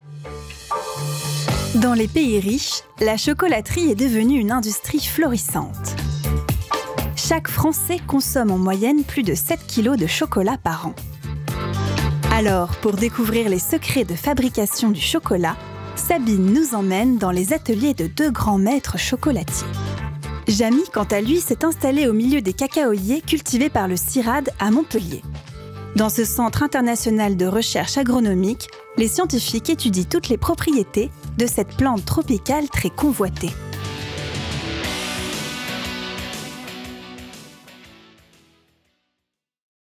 Voix off
Voix douce, jeune, fraîche, souriante, médium.
10 - 40 ans - Mezzo-soprano